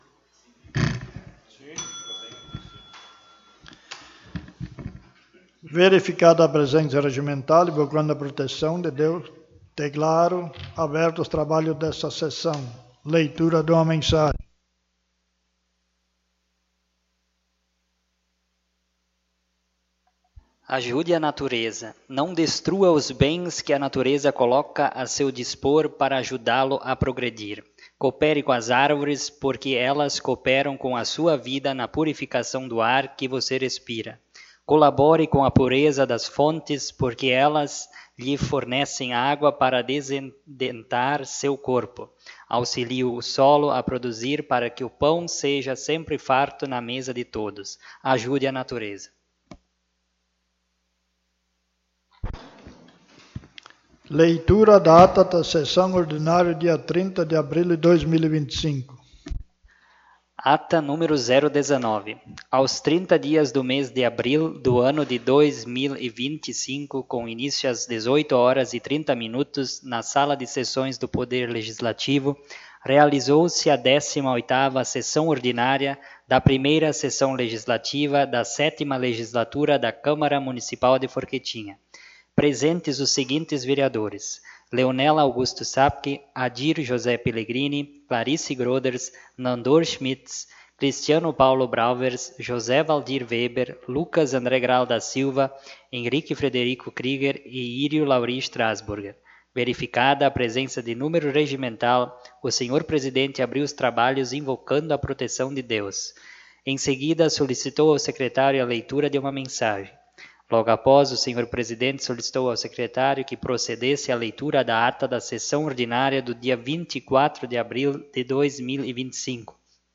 19ª Sessão Ordinária